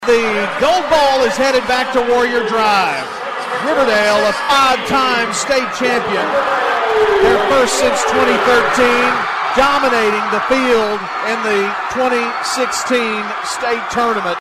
play-by-play